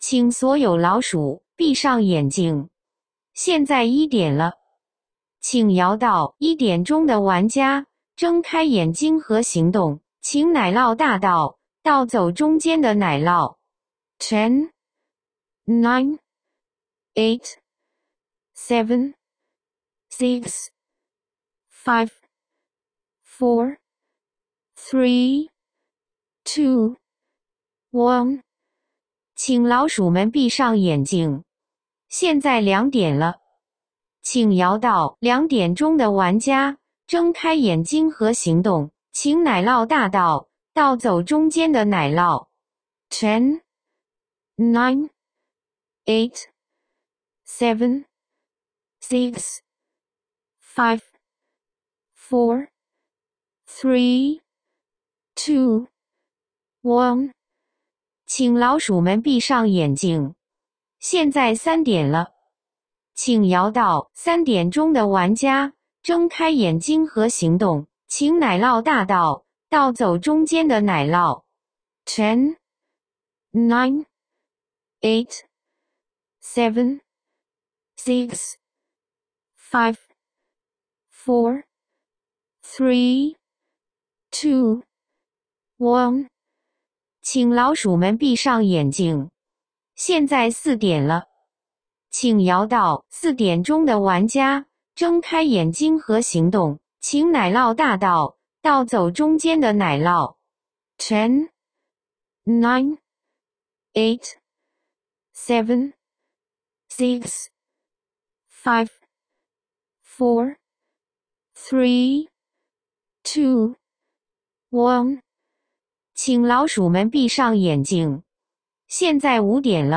奶酪大盗桌游主持人配音，按 4–8 人局选择，可直接播放与下载。
cheese_thief_8p_host.m4a